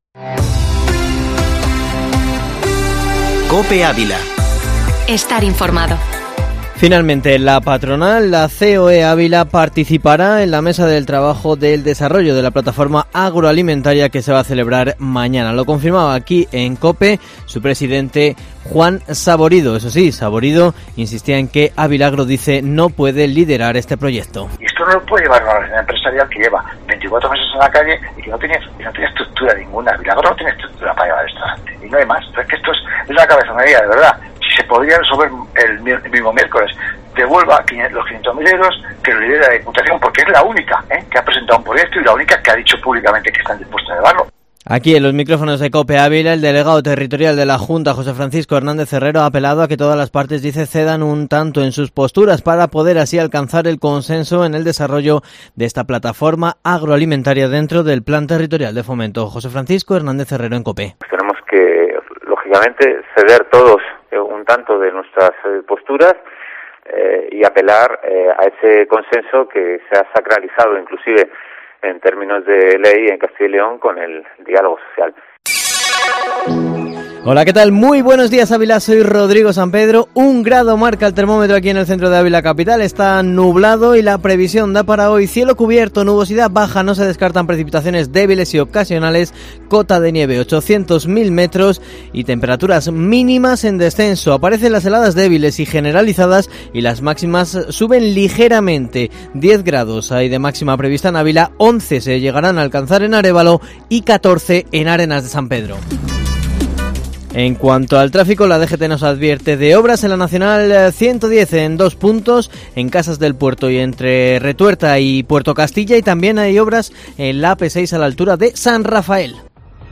Informativo matinal Herrera en COPE Ávila 09/03/2021